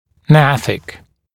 [ˈnæθɪk][ˈнэсик]гнатический, челюстной; относящийся к альвеолярному отростку челюсти
gnathic.mp3